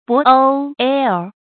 渤澥桑田 注音： ㄅㄛˊ ㄒㄧㄝ ˋ ㄙㄤ ㄊㄧㄢˊ 讀音讀法： 意思解釋： 渤澥：渤海的古稱。